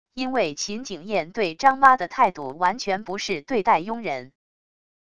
因为秦景宴对张妈的态度完全不是对待佣人wav音频生成系统WAV Audio Player